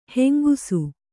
♪ hengusu